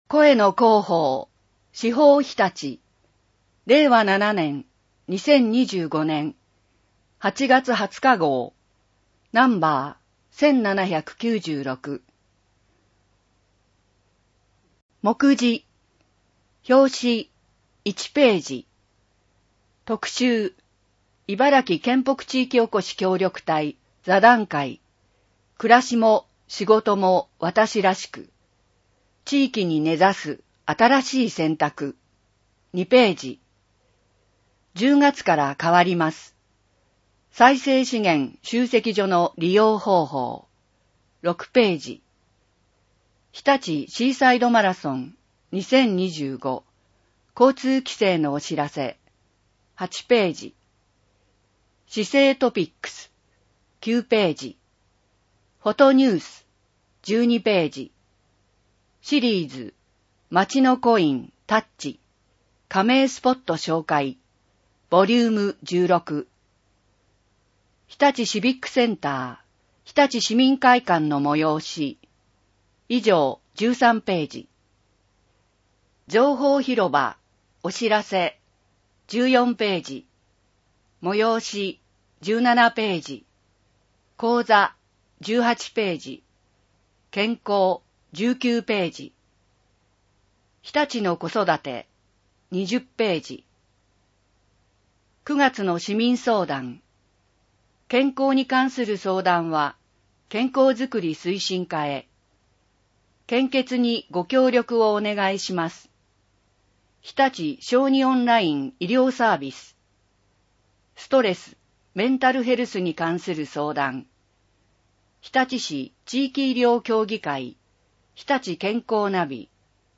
日立市コミュニティ推進協議会設立50周年記念式典 24ページ （PDF 1.7MB） 電子書籍 イバラキイーブックス （外部リンク） 声の市報 声の市報を読みあげます。